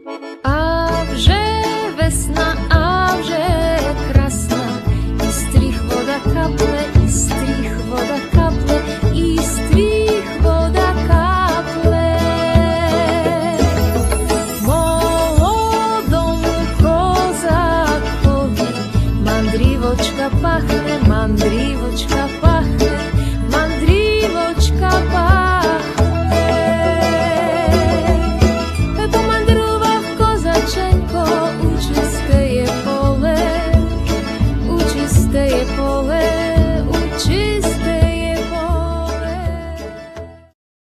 sopiłka, flet poprzeczny
mandolina
gitara
skrzypce
bębny, djembe
akordeon
kontrabas